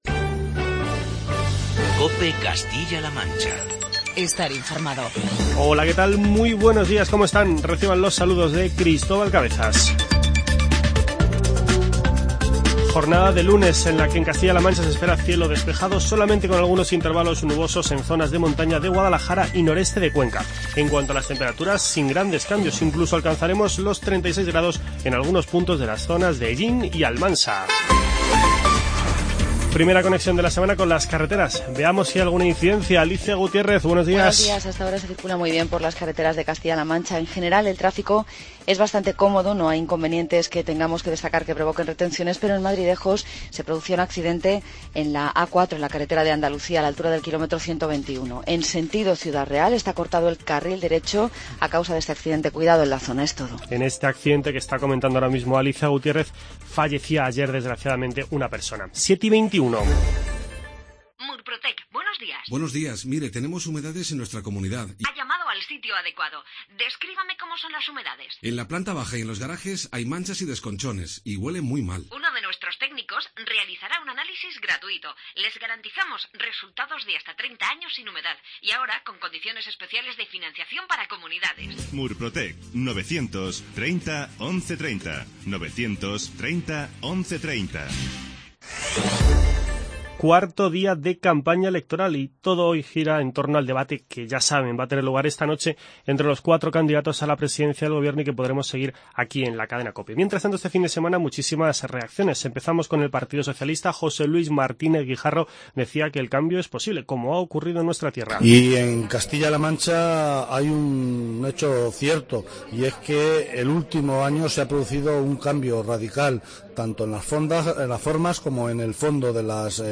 Informativo regional